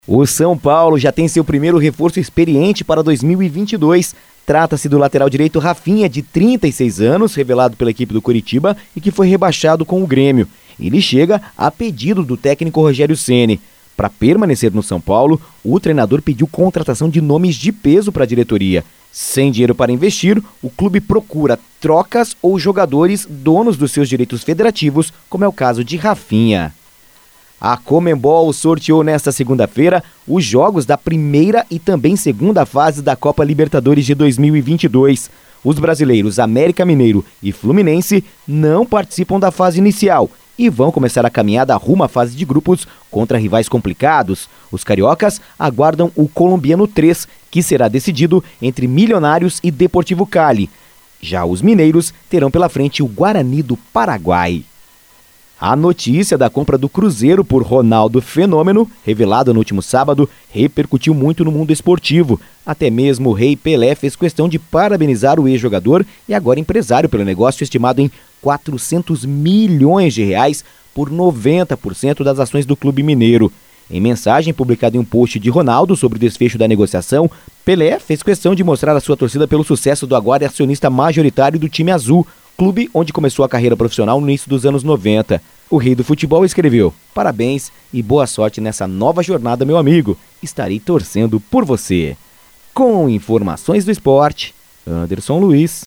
Giro Esportivo (SEM TRILHA)